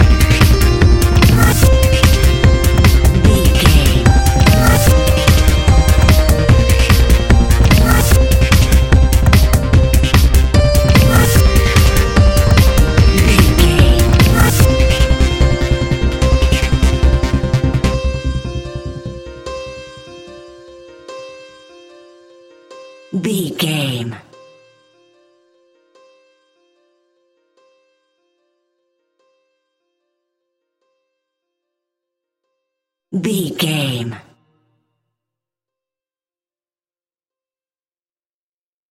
Epic / Action
Fast paced
Aeolian/Minor
Fast
groovy
uplifting
driving
energetic
repetitive
piano
drum machine
acid trance
synth leads
synth bass